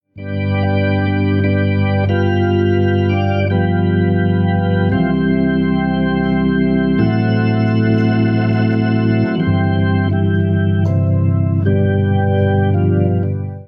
Eine Art Schlüsselerlebnis hatte ich, als ein Kirchenorganist in etwa folgendes spielte: Das wäre dann ein halbverminderter.